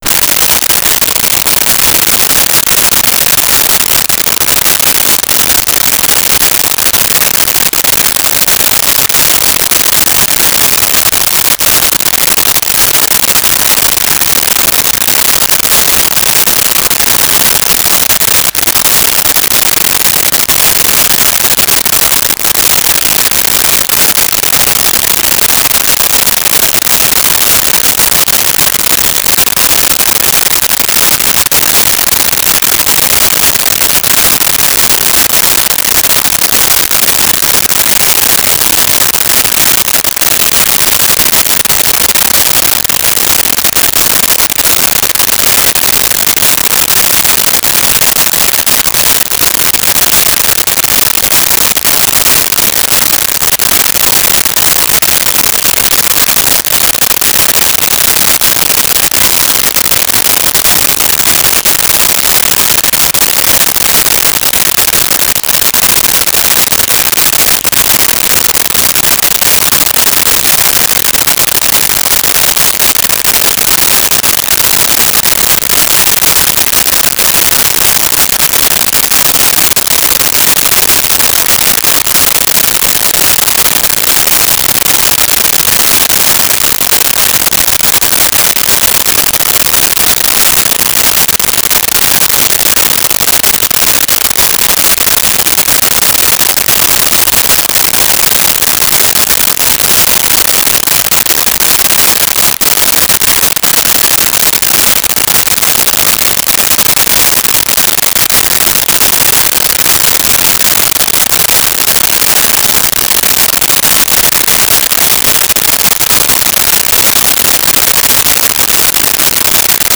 Rain Into Puddle
Rain Into Puddle.wav